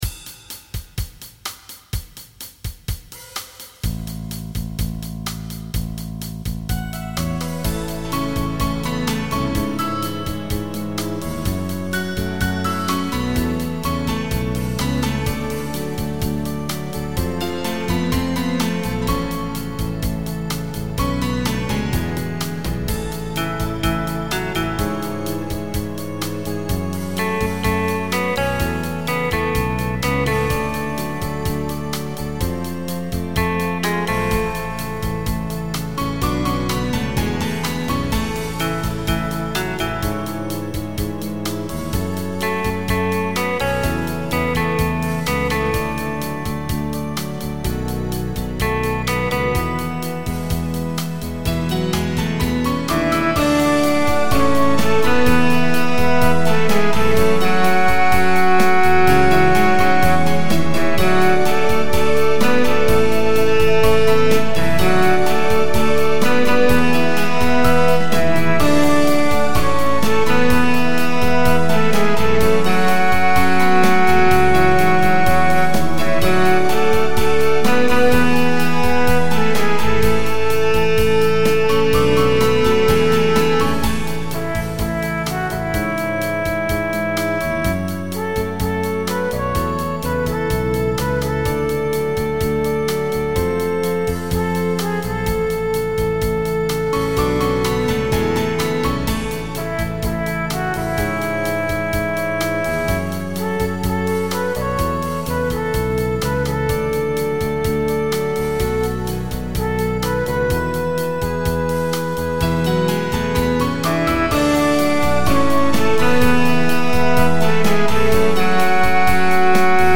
Recording from MIDI